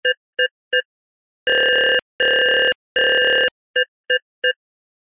sos.ogg